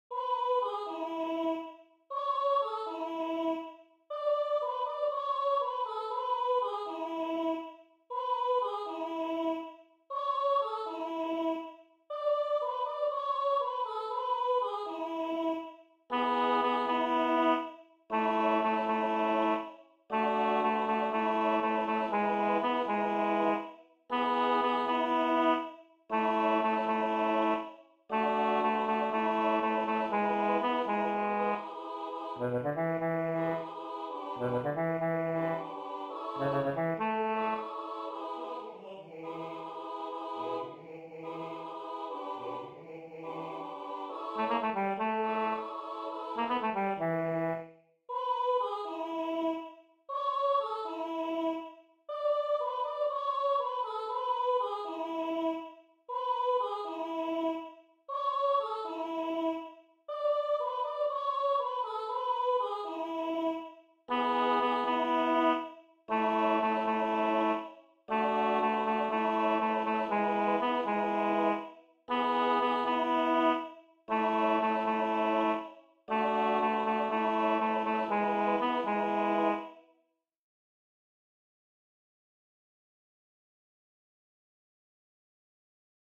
Baryton